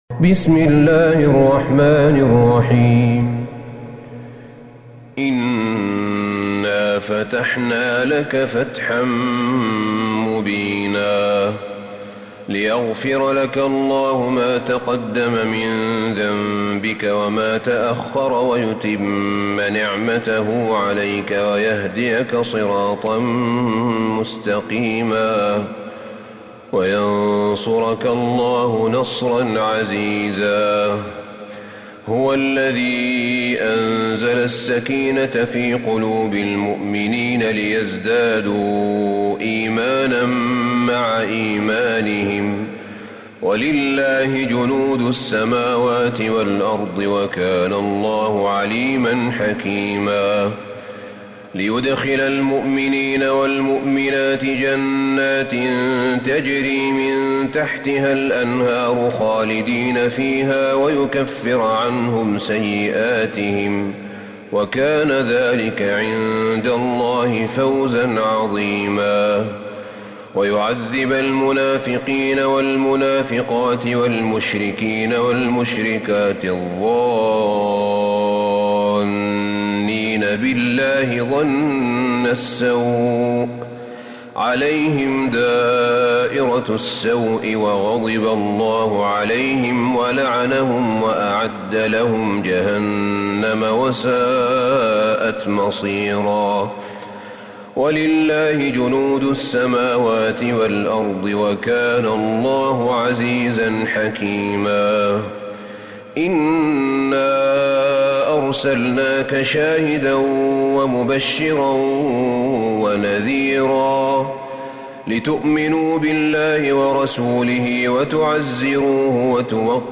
سورة الفتح Surat Al-Fath > مصحف الشيخ أحمد بن طالب بن حميد من الحرم النبوي > المصحف - تلاوات الحرمين